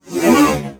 ROBOTIC_Movement_05_mono.wav